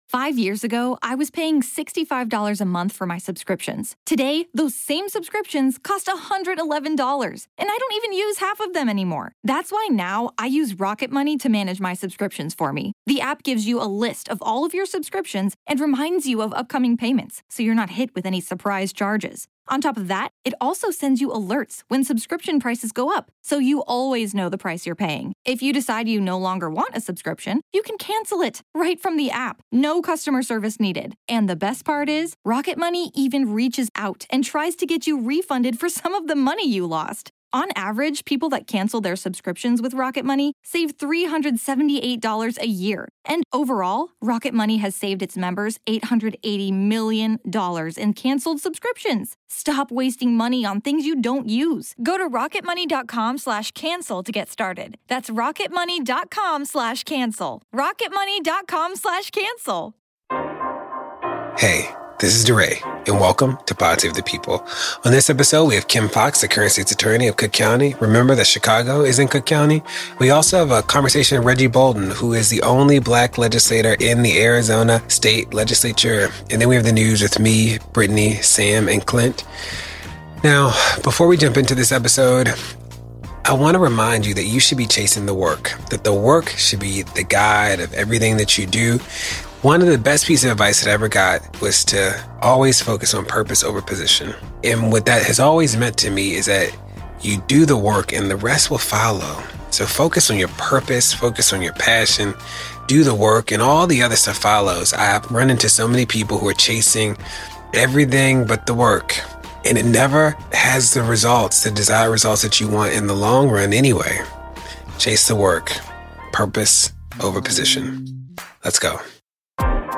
DeRay sits down for a personal conversation with Cook County State’s Attorney Kim Foxx to talk about her path, bond and bail systems, the pace of justice, and her vision.
AZ State Senator Reggie Bolding processes Arpaio’s pardon with DeRay.